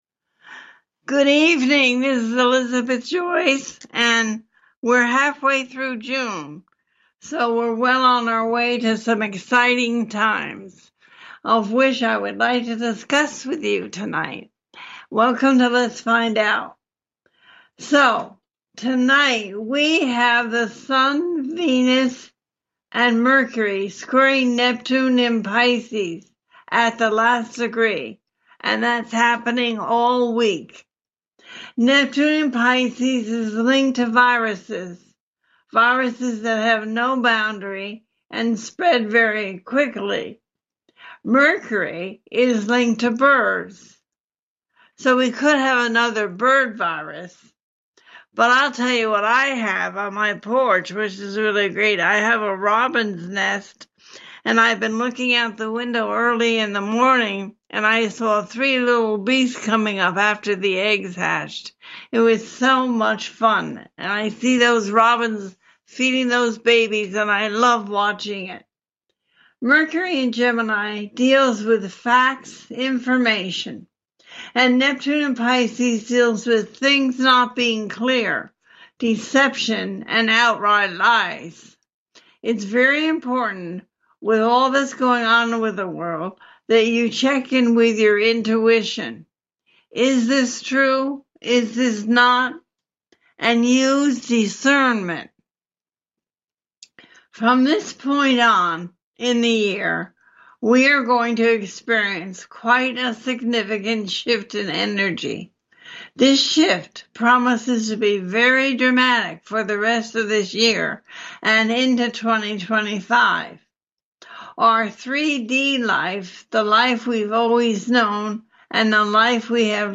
June Events 2024, A teaching show.